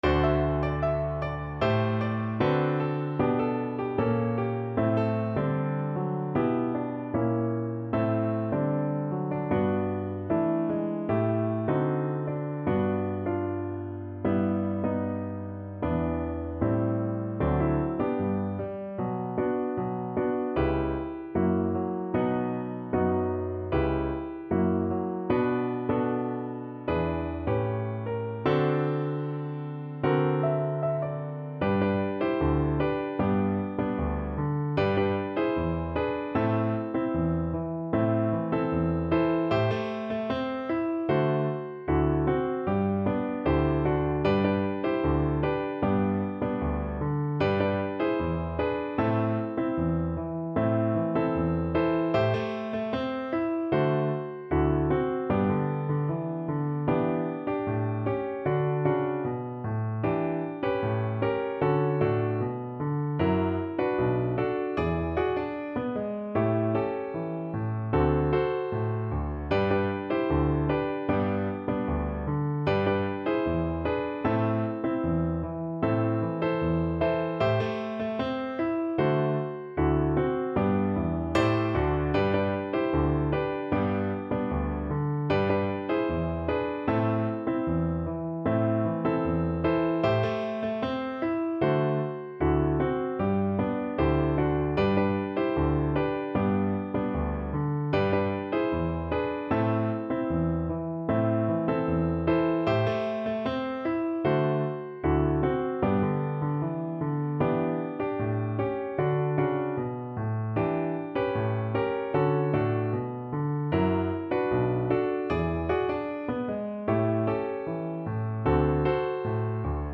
Pop (View more Pop Violin Music)